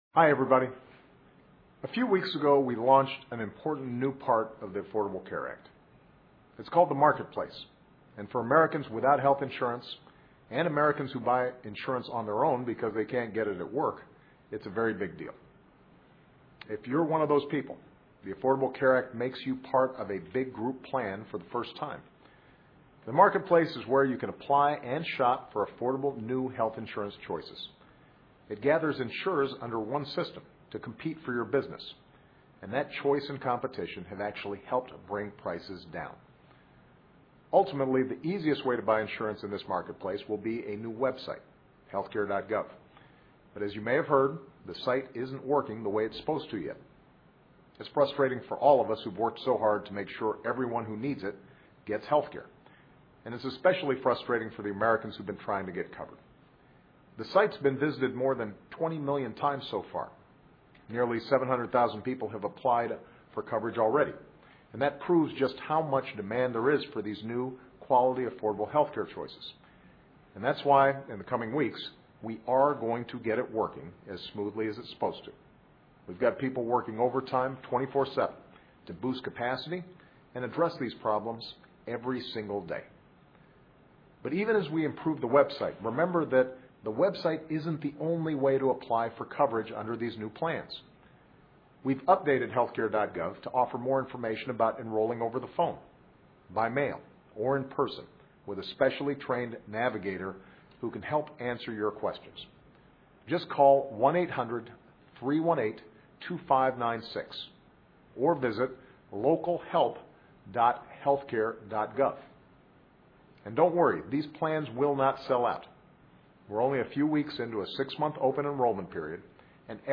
奥巴马每周电视讲话：总统呼吁全美人民支持《平价医保法》 听力文件下载—在线英语听力室